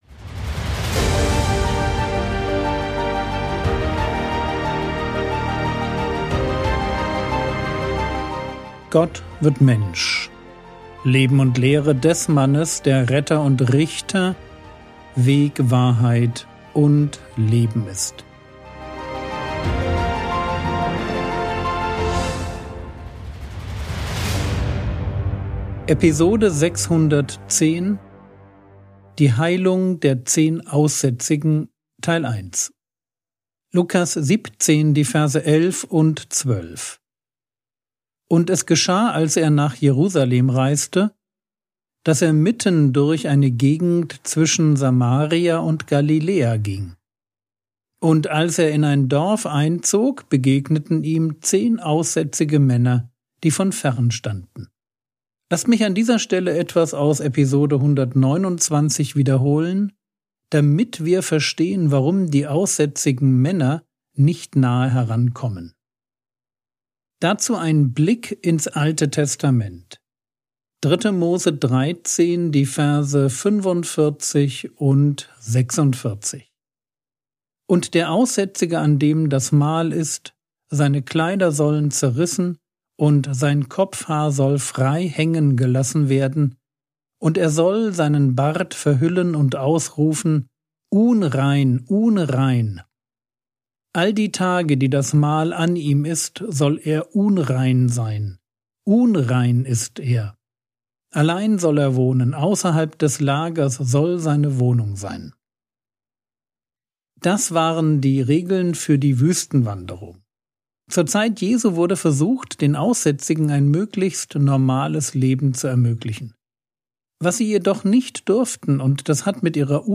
Episode 610 | Jesu Leben und Lehre ~ Frogwords Mini-Predigt Podcast